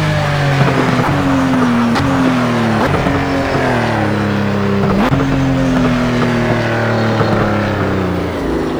slowdown_highspeed.wav